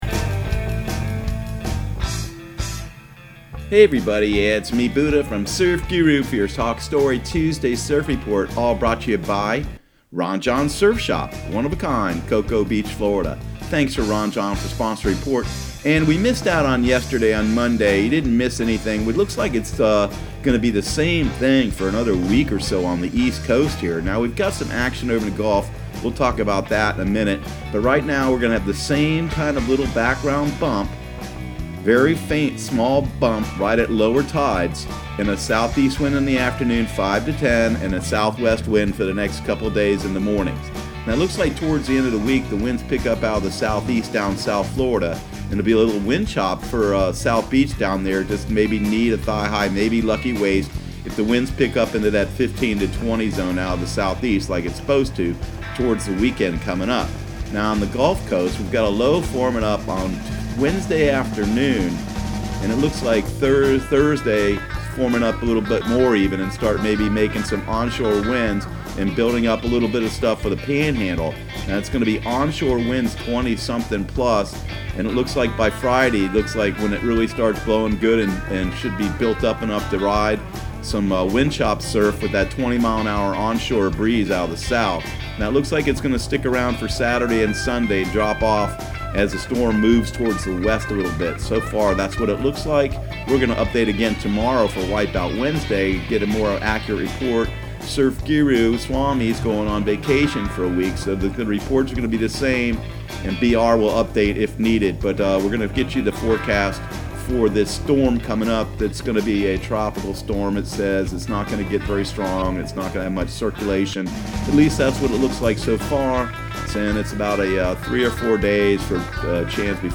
Surf Guru Surf Report and Forecast 07/09/2019 Audio surf report and surf forecast on July 09 for Central Florida and the Southeast.